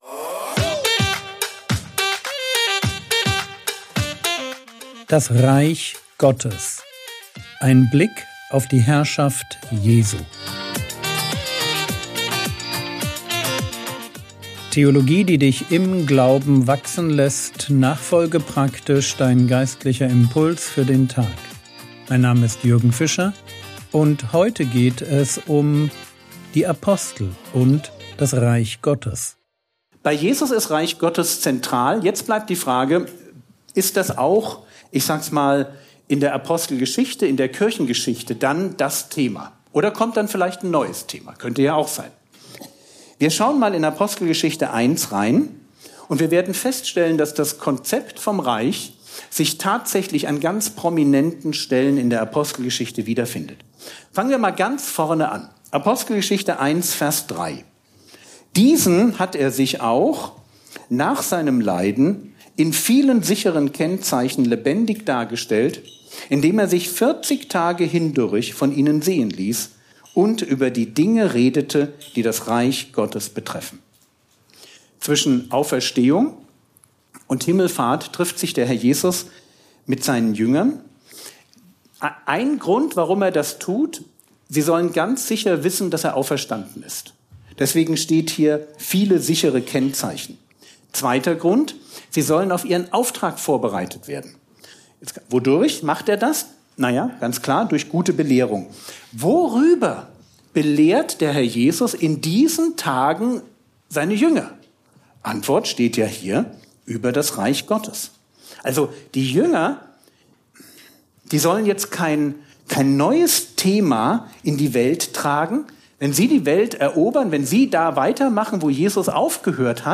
Vortrag Paderborn